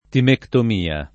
timectomia [ timektom & a ] s. f. (med.)